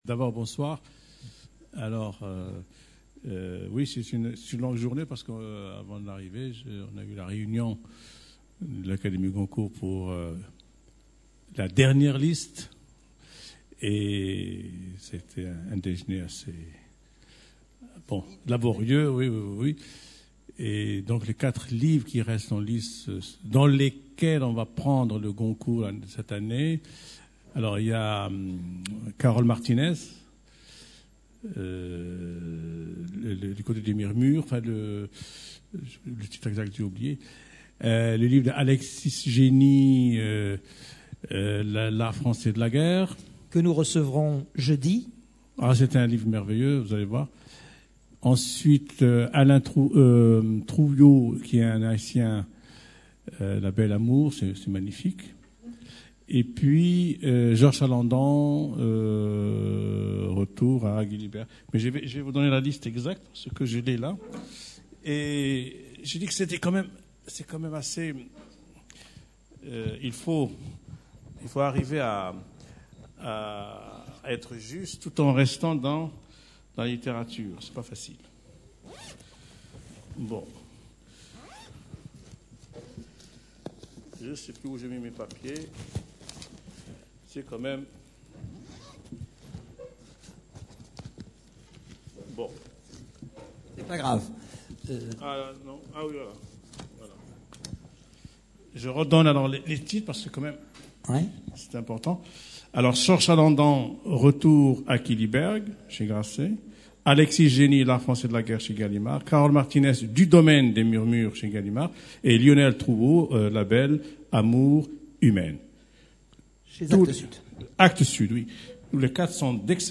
Ben Jelloun, Tahar. Personne interviewée
Rencontre littéraire